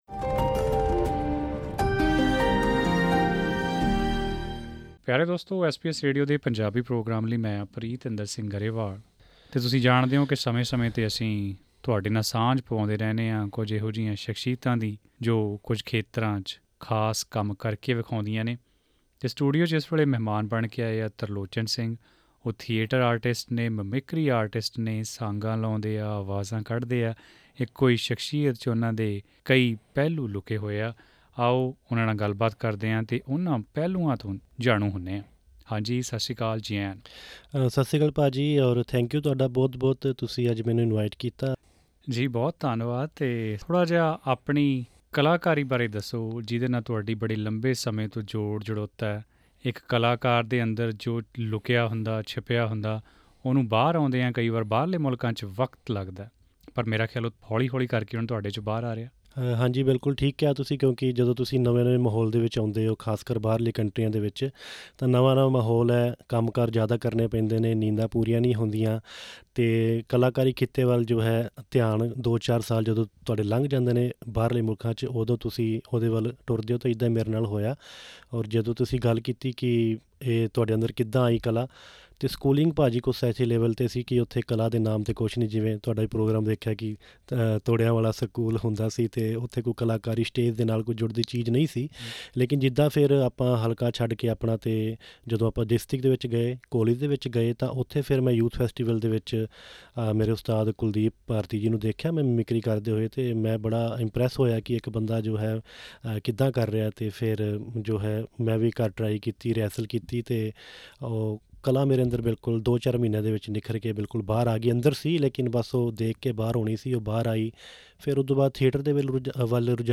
ਜ਼ਿਆਦਾ ਜਾਣਕਰੀ ਲਈ ਉਨ੍ਹਾਂ ਨਾਲ਼ ਕੀਤੀ ਇਹ ਇੰਟਰਵਿਊ ਸੁਣੋ.....